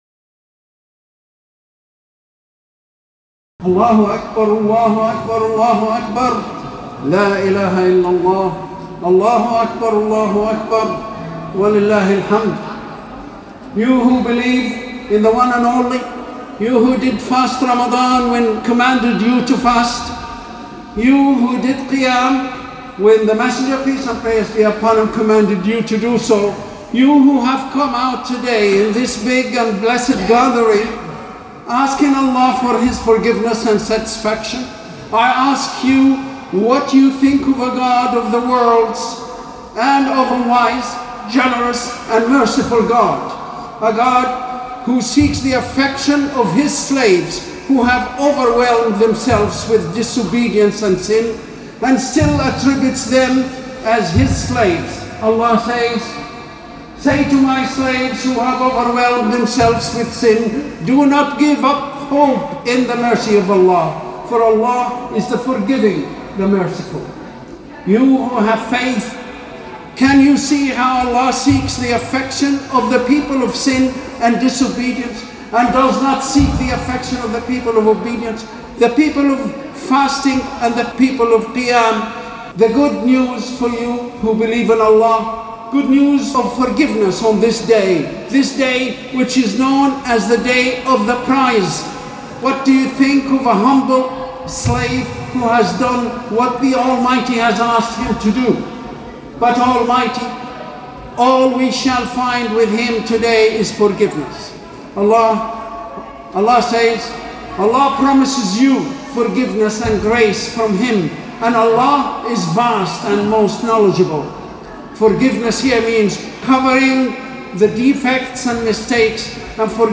Eid Fitr Talks